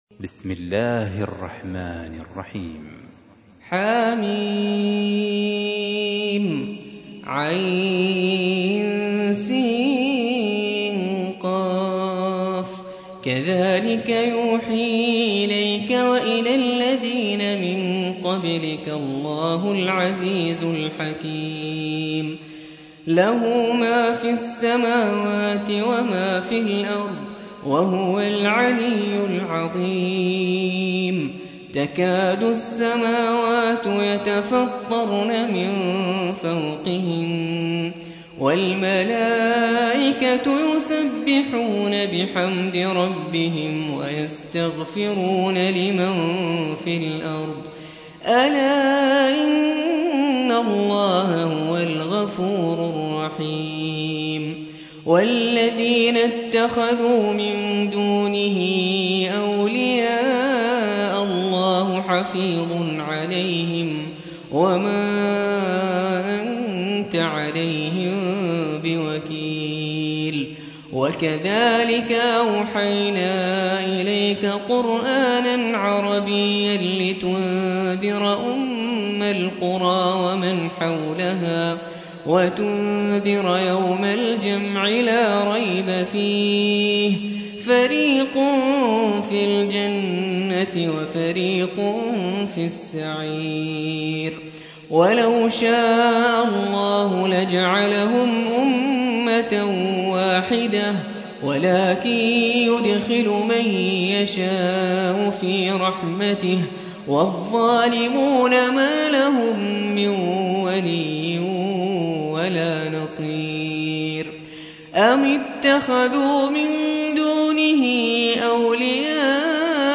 42. سورة الشورى / القارئ